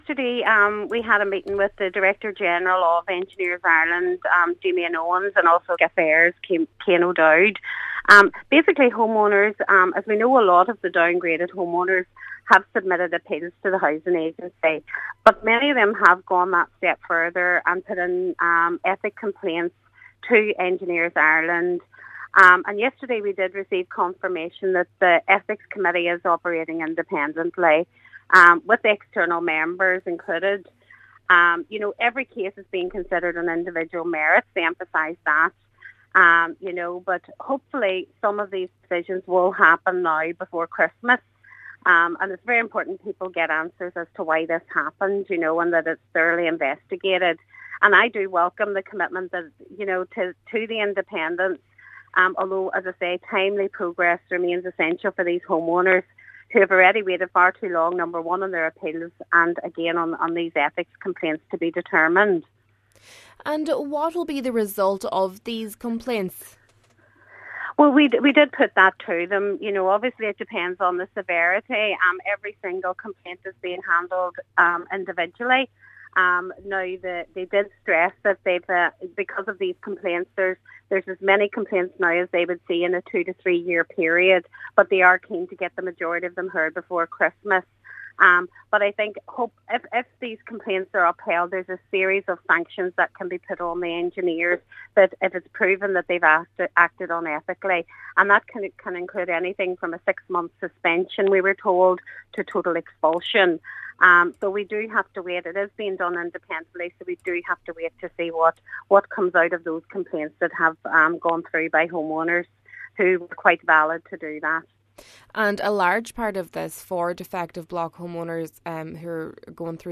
Councillor Joy Beard says it’s vital that the process is timely and transparent: